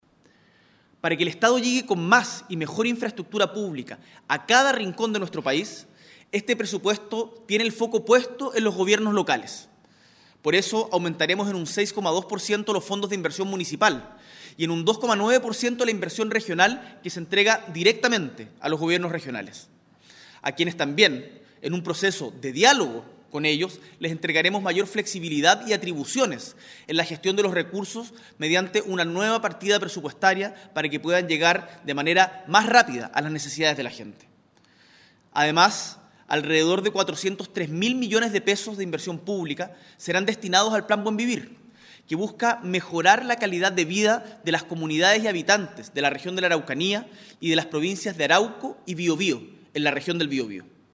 A través de una cadena nacional, el Presidente de la República, Gabriel Boric, entregó los detalles de la primera Ley de Presupuesto de su Gobierno, para el 2023.